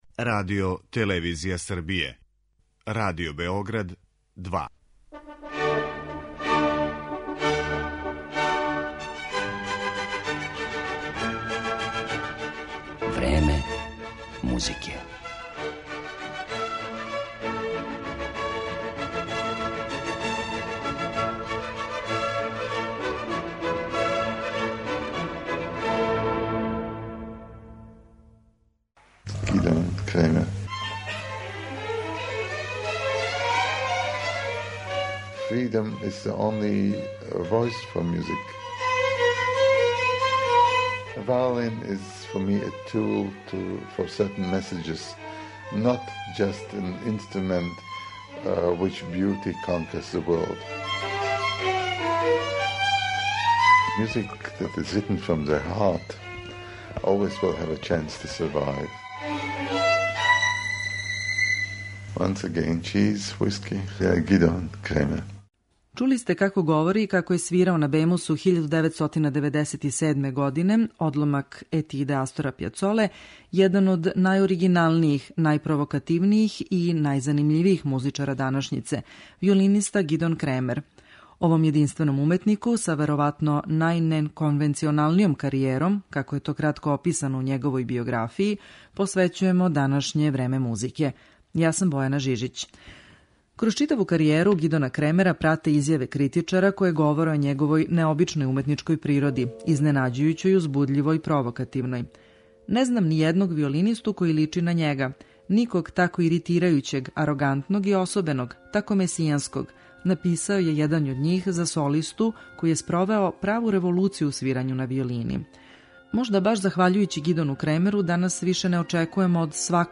Њему је посвећена данашња емисија, у којој ћемо овог славног солисту представити и кроз одломке интервјуа сниманих са њим у периоду од 1994. до 2007. године.